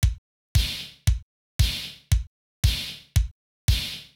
MOO Beat - Mix 10.wav